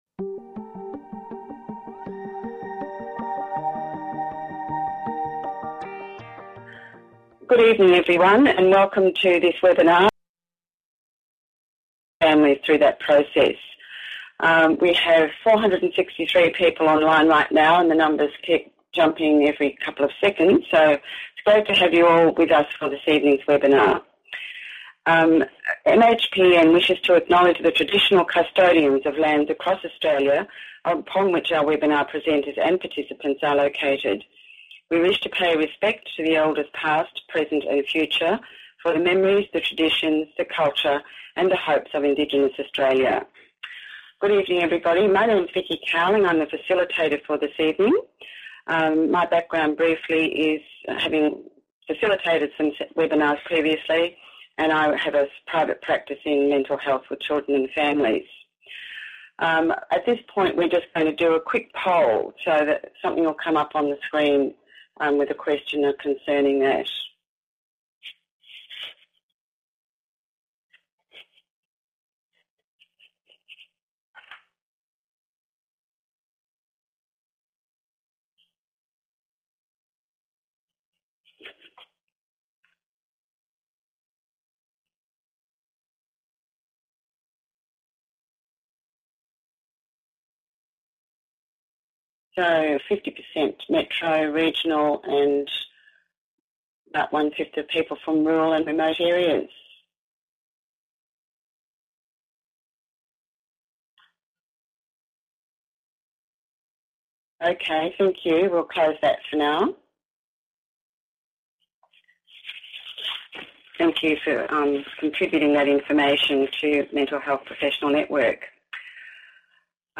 Join our interdisciplinary panel of experts for a discussion on collaborative care may help support families who are going through separation.